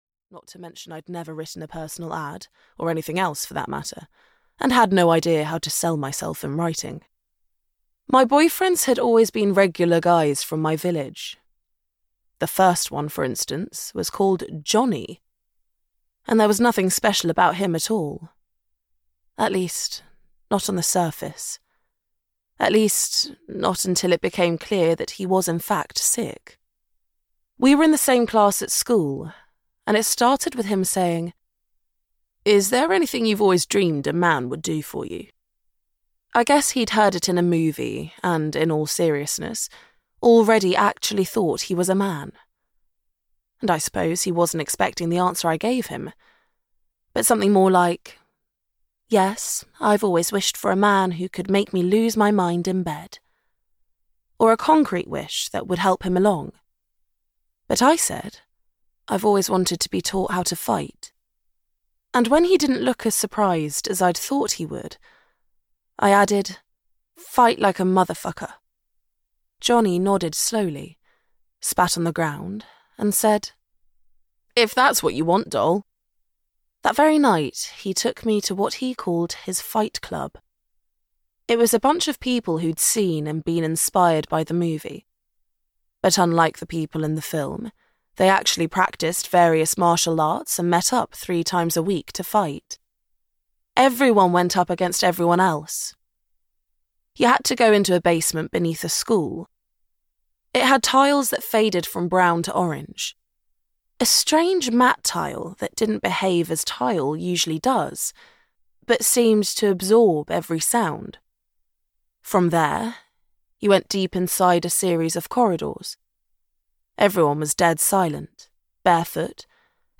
The Polyglot Lovers (EN) audiokniha
Ukázka z knihy